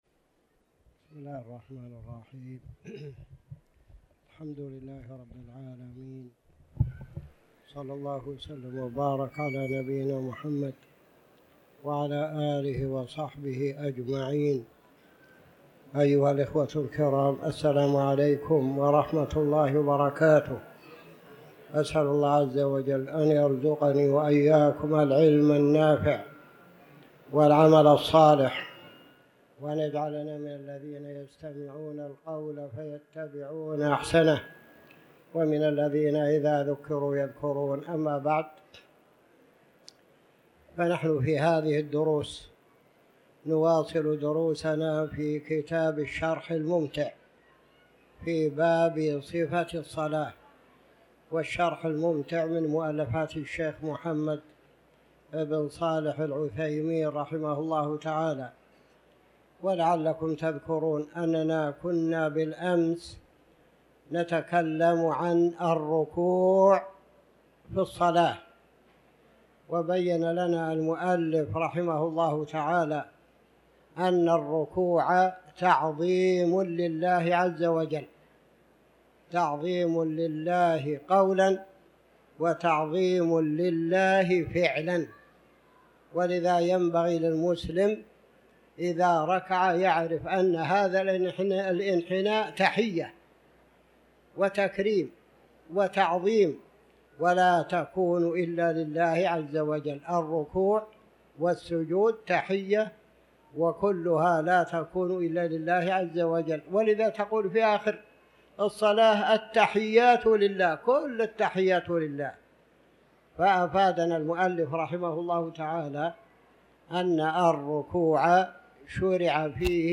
تاريخ النشر ٦ ذو القعدة ١٤٤٠ هـ المكان: المسجد الحرام الشيخ